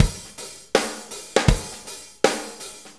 Shuffle Loop 23-11.wav